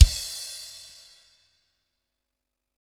BREAK.wav